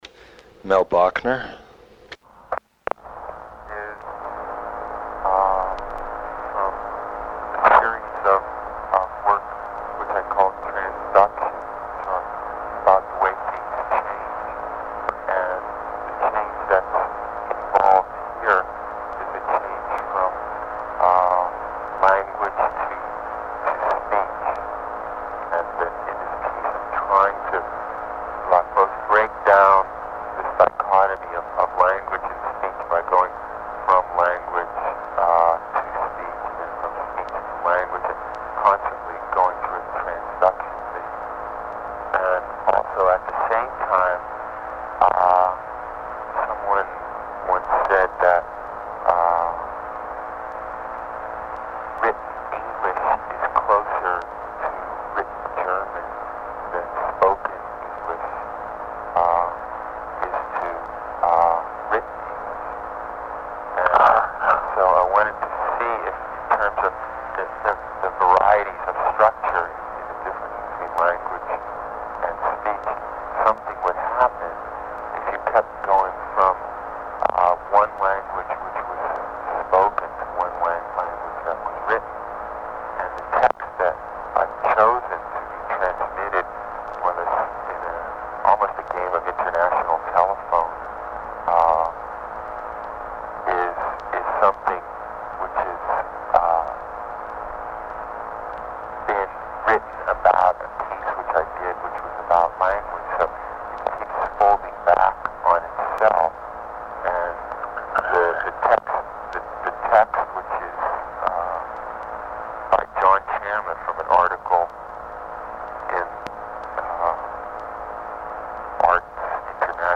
His « Transduction » explores the changes in an idea structure as it is communicated through multilingual channels. He has phoned in a paragraph of art criticism with the instruction that the text be read to a contact in Italy who is to translate it and read it to a contact in Germany who, in turn, is to translate and read it to a contact in Sweden who is to translate and read it to a contact in England.
audio extrait du vinyle de 1969